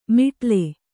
♪ miṭale